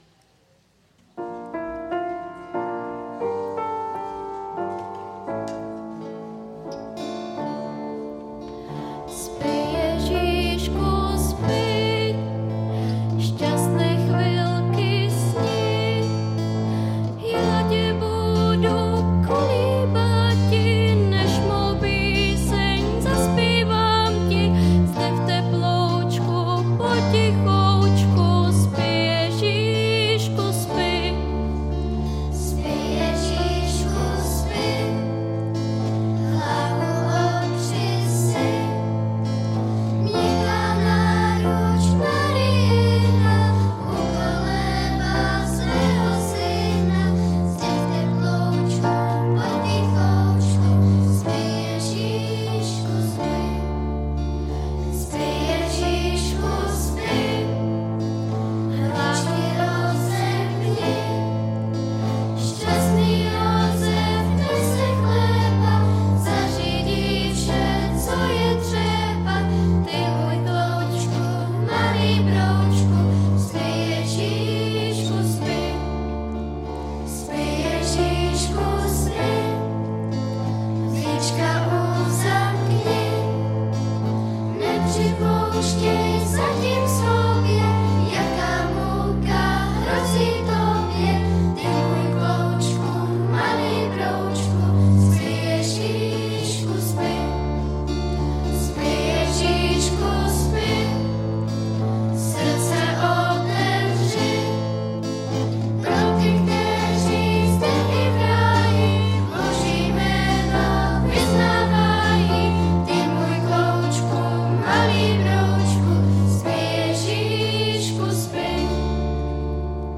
Třetí adventní neděle - Dětská vánoční slavnost